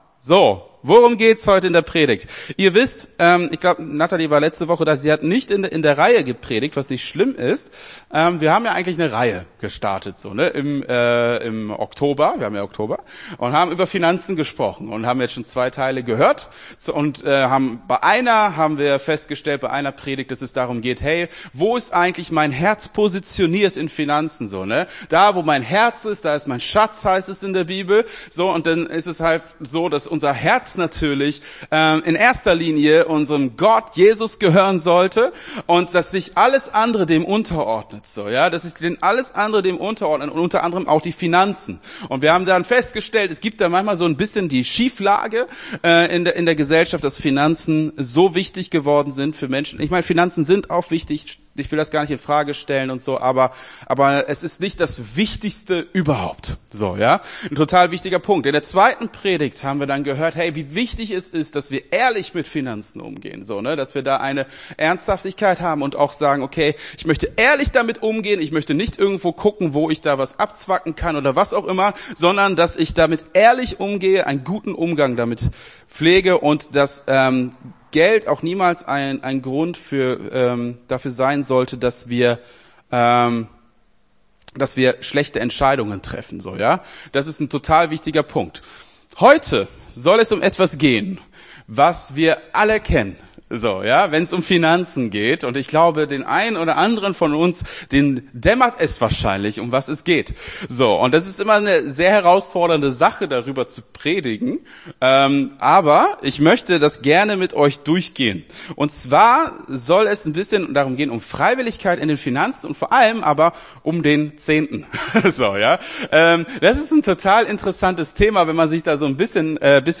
Gottesdienst 23.10.22 - FCG Hagen